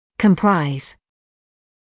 [kəm’praɪz][кэм’прайз]состоять из, включать в себя; составлять